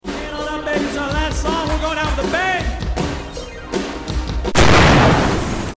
Oops sound effects free download